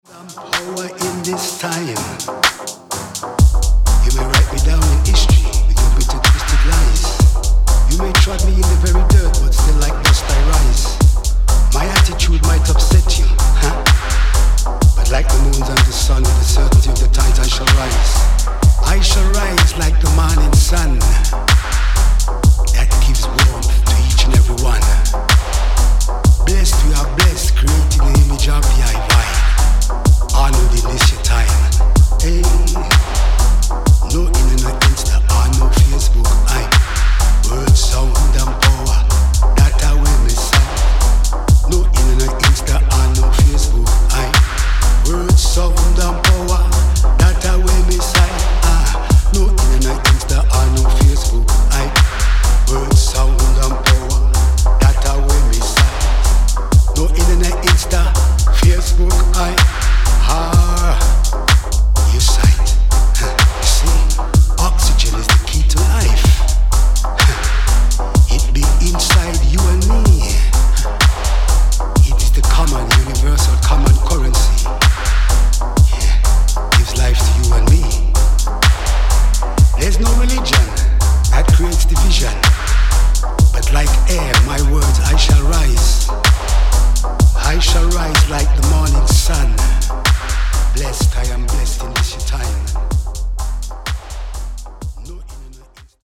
ダークなコードが支配するハーフタイムのグルーヴがカッコ良し。